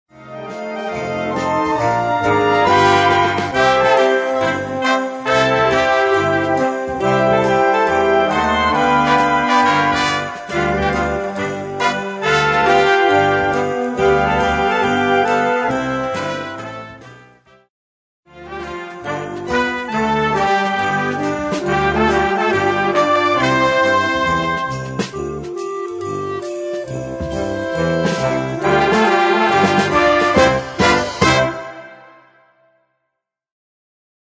Gattung: Weihnachtsmusik
Besetzung: Blasorchester